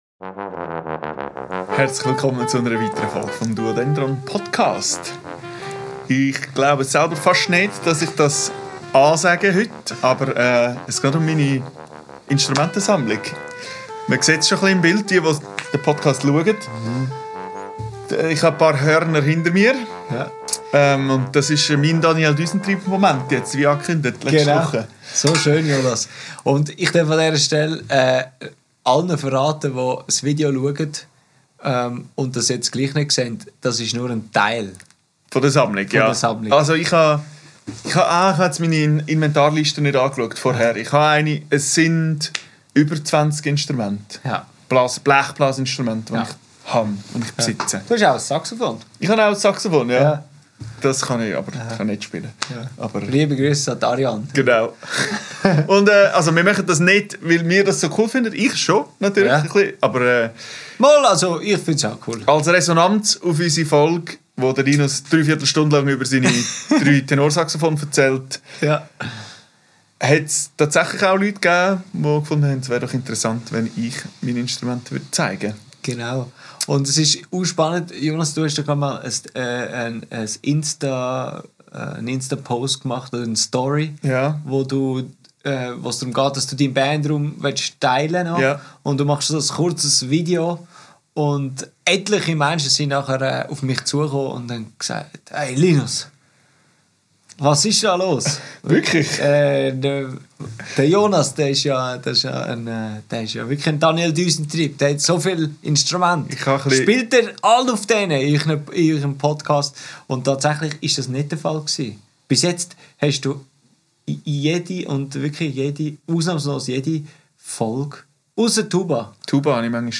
Aufgenommen am 22.05.2025 im Atelier